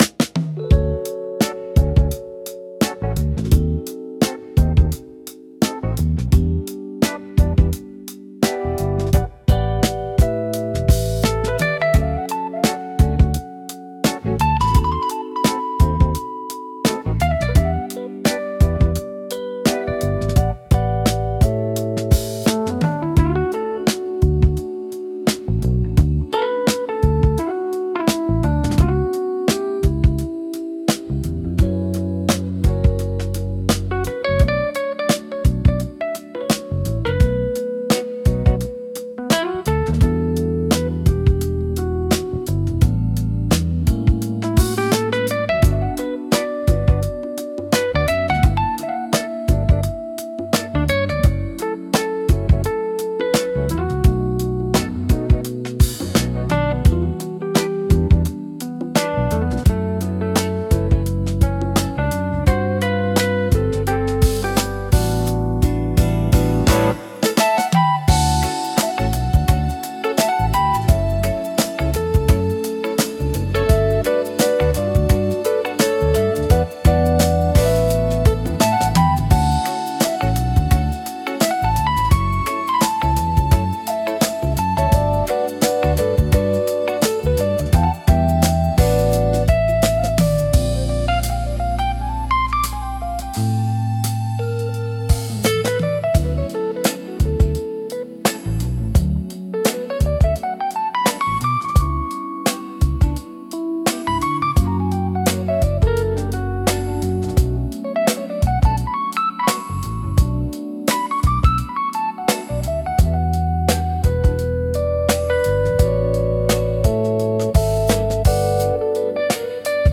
穏やか
イージーリスニング , エレピ , エンディング , ワールド , 昼 , 秋 , 穏やか , 静か